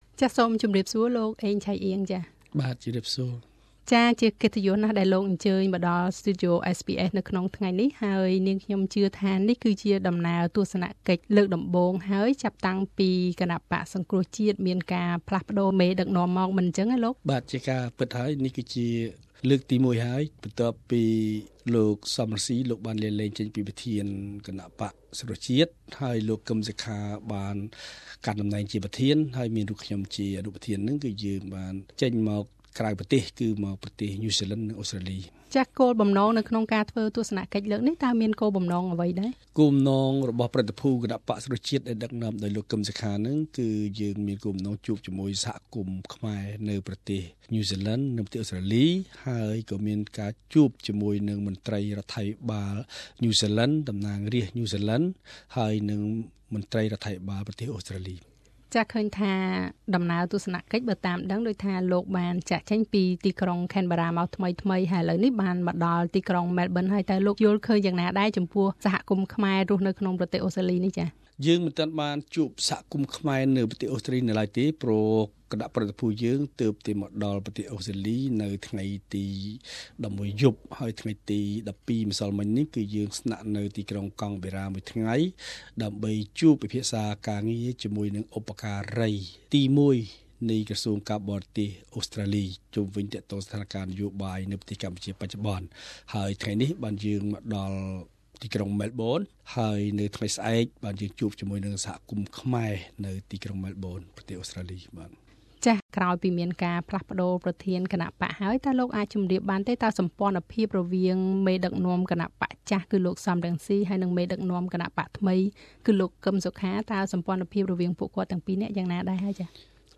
សូមស្តាប់បទសម្ភាសជាមួយនឹងលោកអេង ឆៃអ៊ាង អនុប្រធានគណបក្សសង្រ្គោះជាតិនិងជាអ្នកតំណាងជំនួសលោកកឹមសុខាដូចតទៅ។ Share